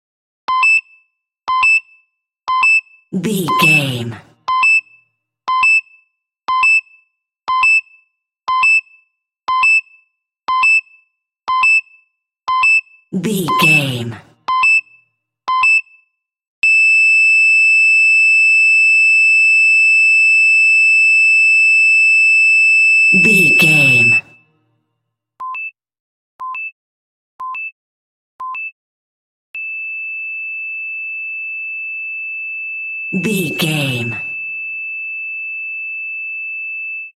Emergency Heartbeat Monitor Double Beeps Flat
Sound Effects
anxious
dramatic